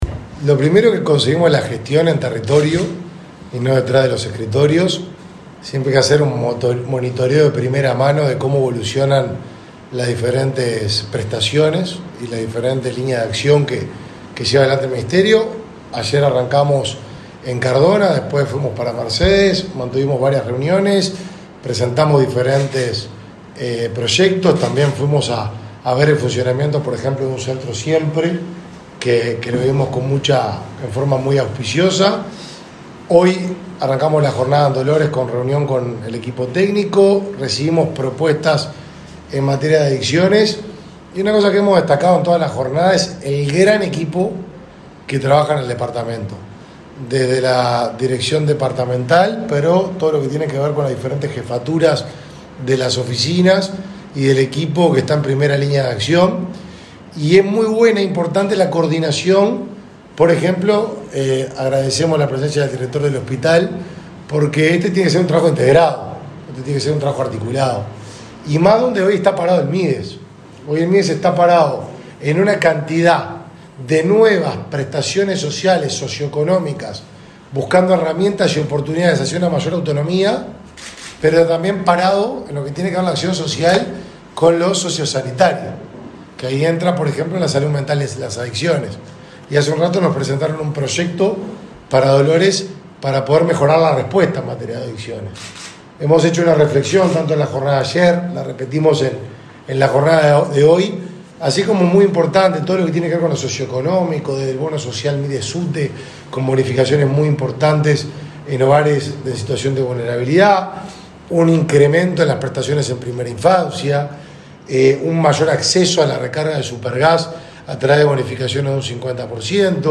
Declaraciones del ministro de Desarrollo Social, Martín Lema
El ministro de Desarrollo Social, Martín Lema, dialogó con la prensa durante una recorrida que realiza este viernes 21 por Dolores, en Soriano.